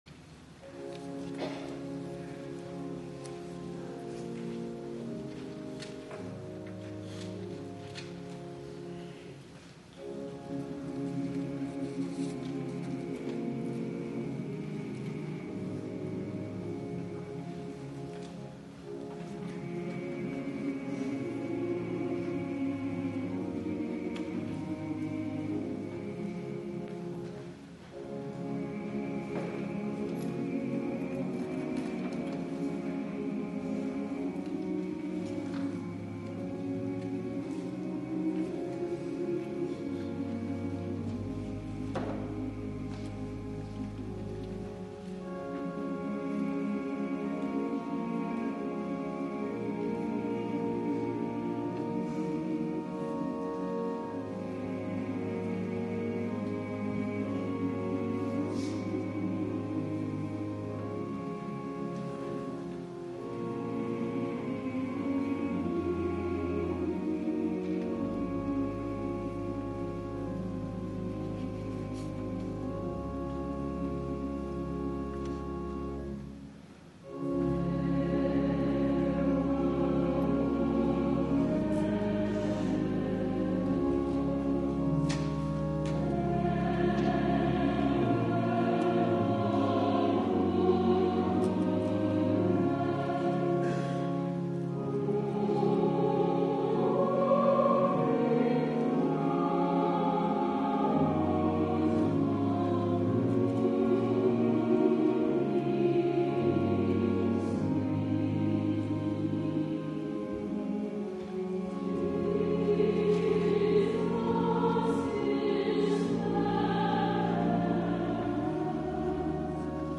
BeautifulSavior_silesian_folk.mp3